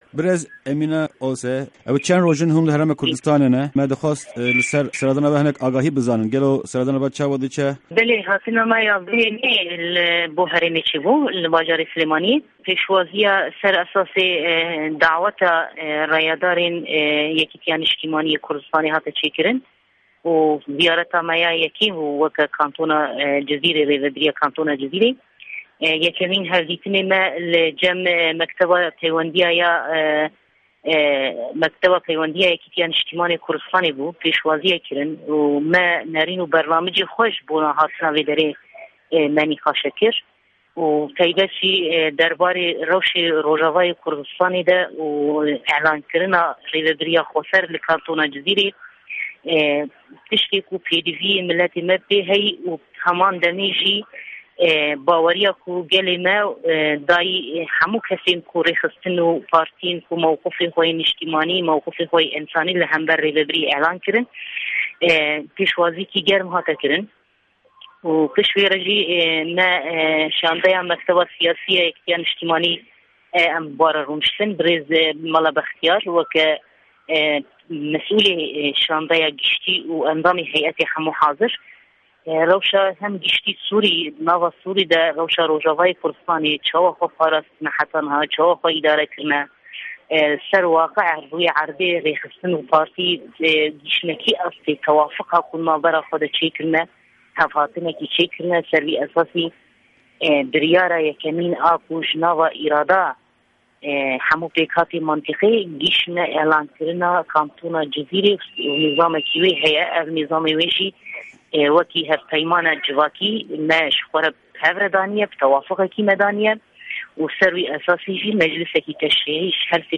Cîgira Berpirsê Komîsyona Derve yê Kantona Cizîre Emîne Osê di hevpeyvîna dEngê Amerîka de agahîyan li ser vê serdanê dike.